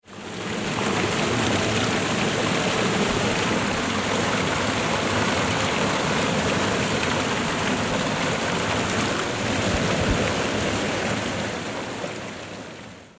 دانلود آهنگ آب 64 از افکت صوتی طبیعت و محیط
جلوه های صوتی
دانلود صدای آب 64 از ساعد نیوز با لینک مستقیم و کیفیت بالا